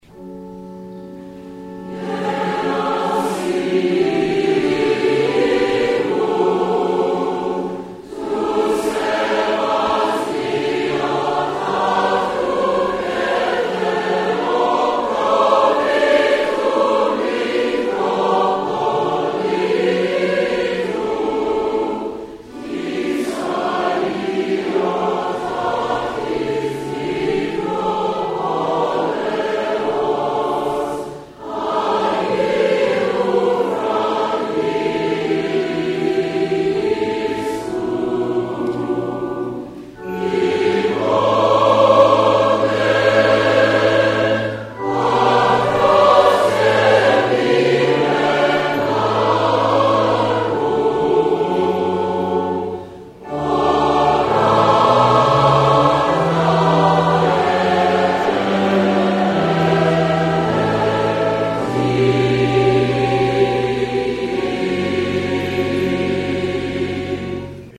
Each hierarch has an "anthem" or "feme" (pronounced FEE-mee, and sometimes spelled "pheme" or "fimi") which proclaims him and the geographic are over which he exercises his canonical ecclesiastical authority.